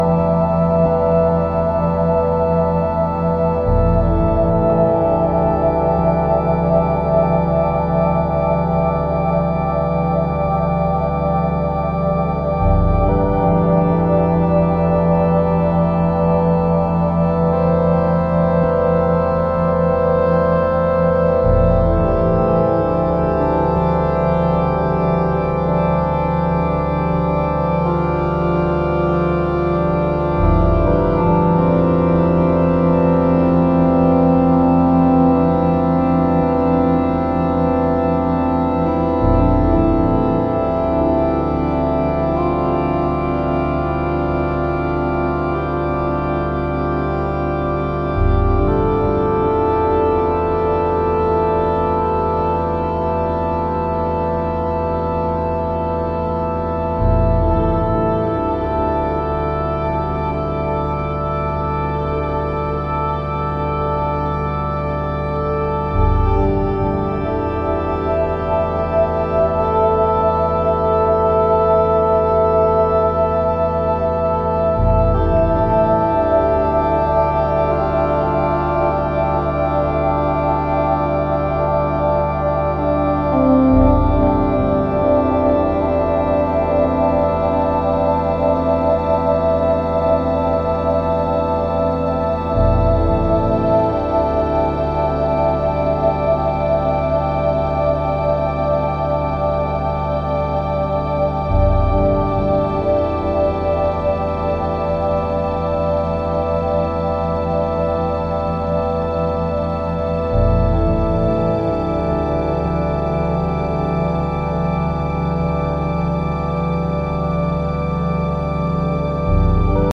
a gorgeous, warm piece of ambient for the chill rooms
Electronix House